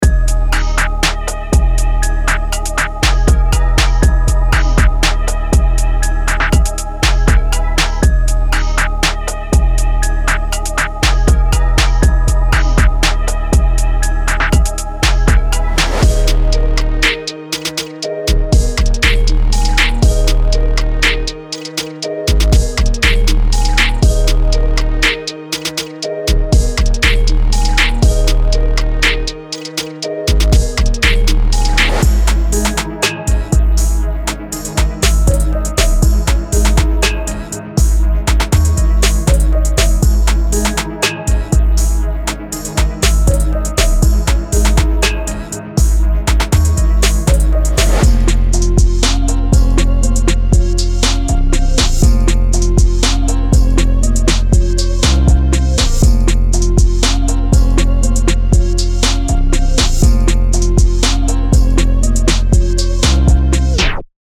Hip-Hop / R&B Trap
• 07 Full Drum Loops;
• 49 Synth Loops;